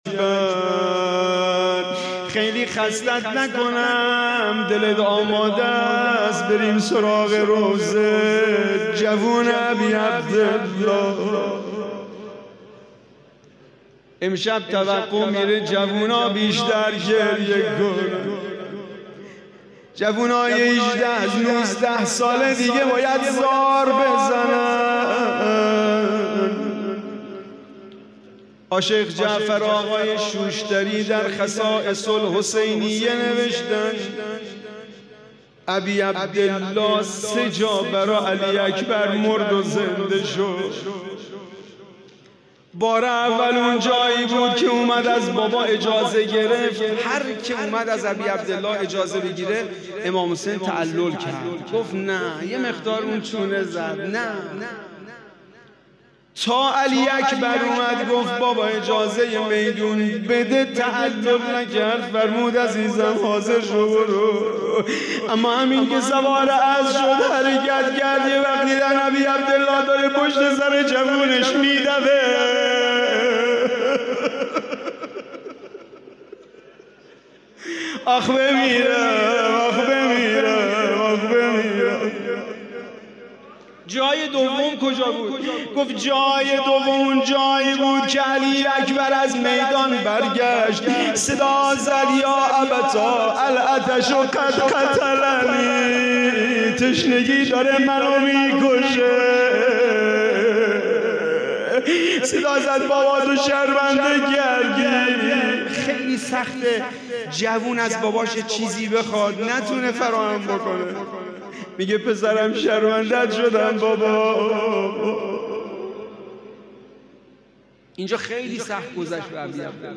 روضه محرم 99